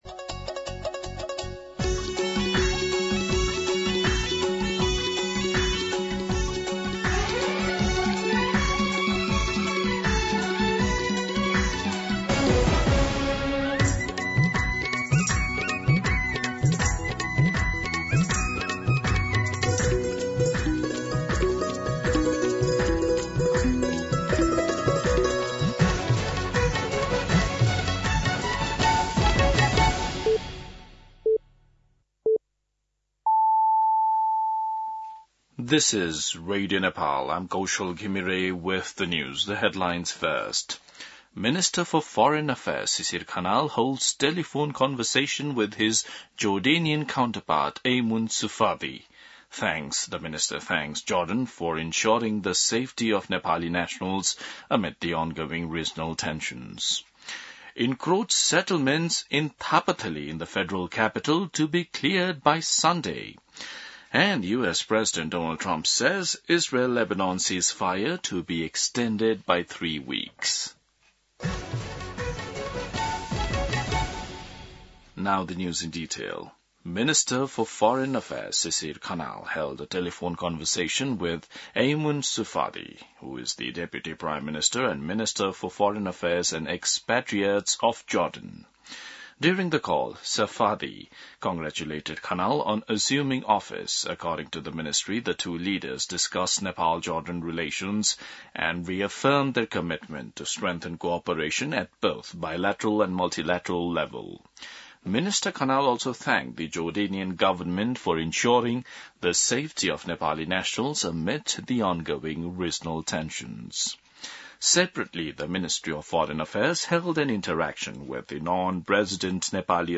दिउँसो २ बजेको अङ्ग्रेजी समाचार : ११ वैशाख , २०८३